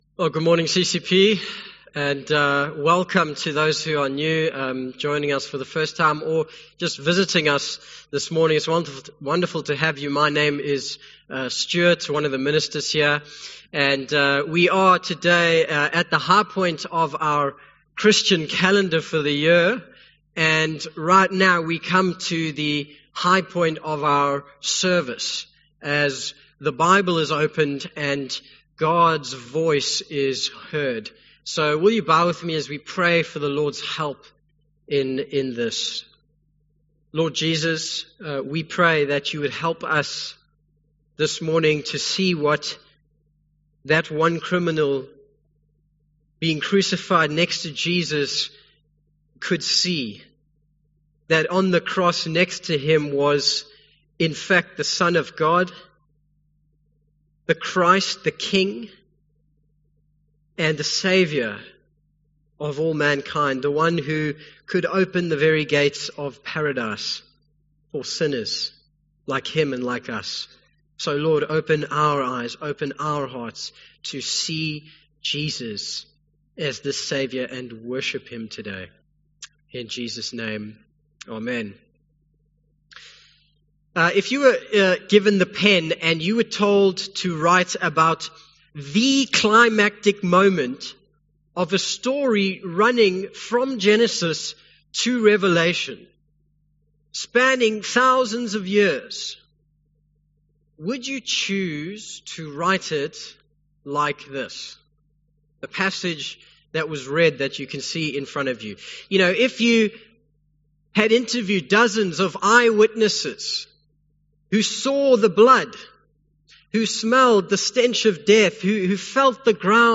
Easter 2023 Passage: Luke 23:32-43 Event: English Sermon Topics